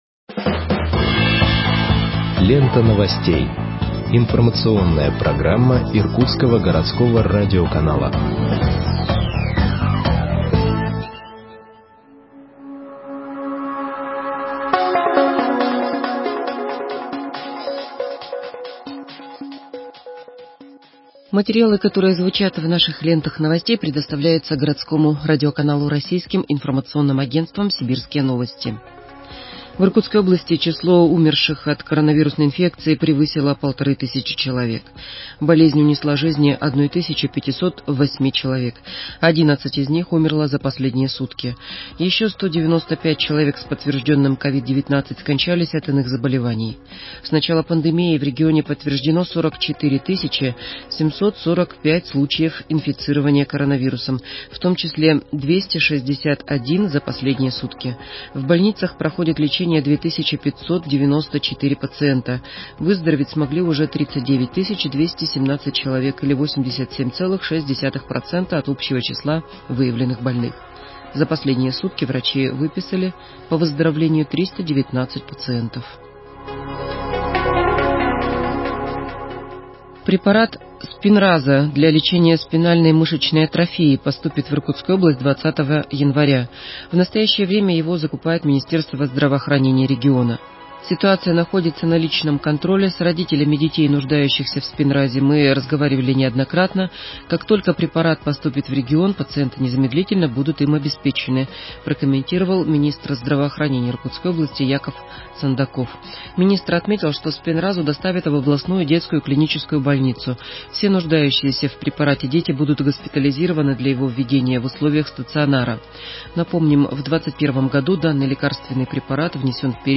Выпуск новостей в подкастах газеты Иркутск от 15.01.2021 № 2